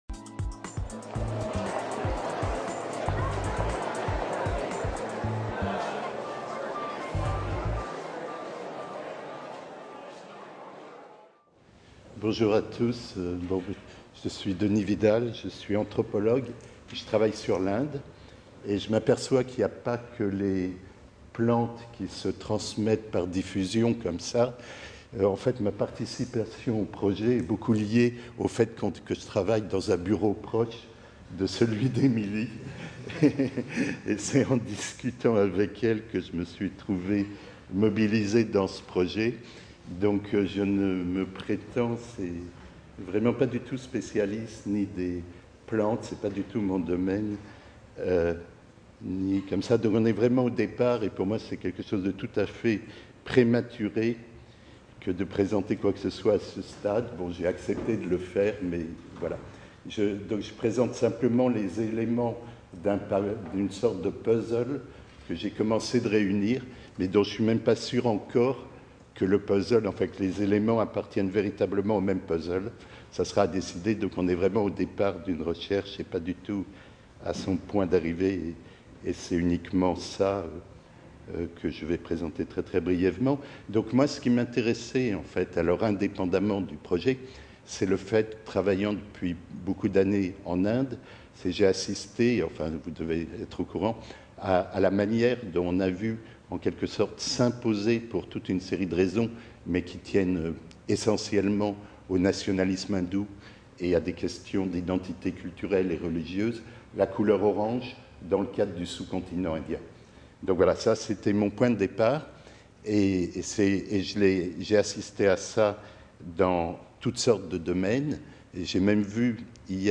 Jeudi 28 mars 2019, Jardin des Plantes, Paris